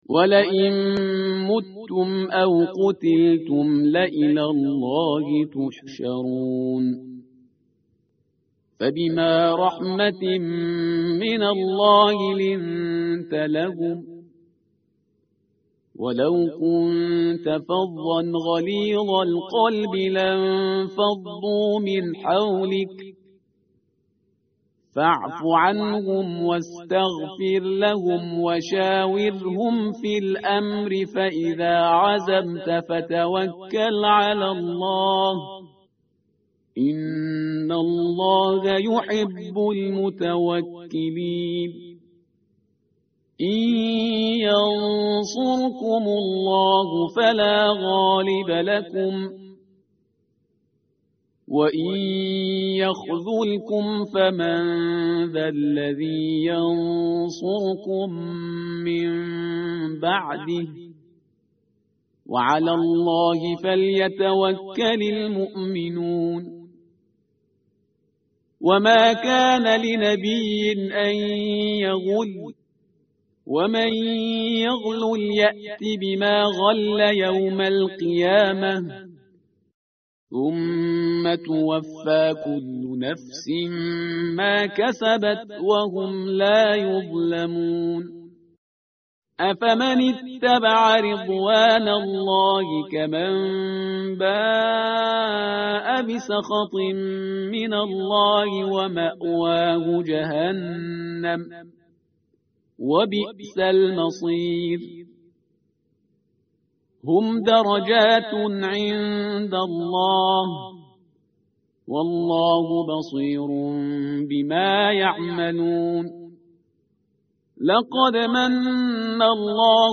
tartil_parhizgar_page_071.mp3